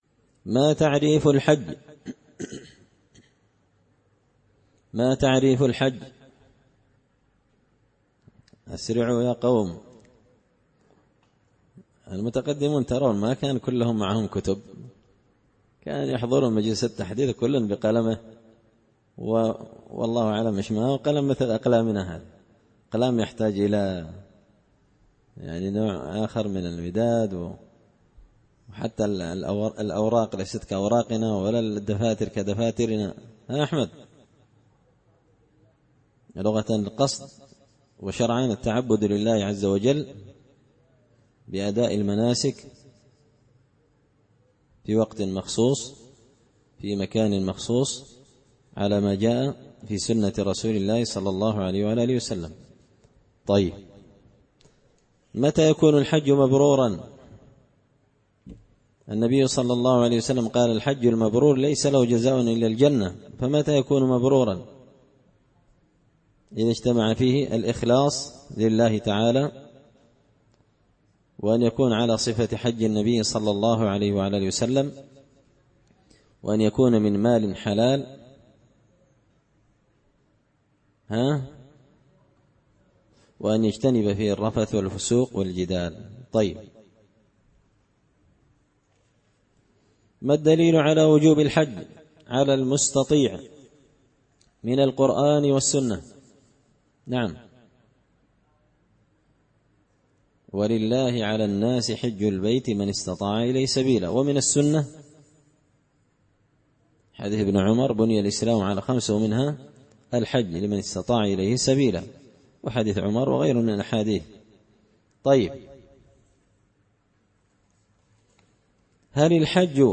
دار الحديث بمسجد الفرقان ـ قشن ـ المهرة ـ اليمن
كتاب-الحج-من-منهج-السالكين-الدرس-الثاني.mp3